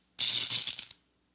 Electricity Sound.wav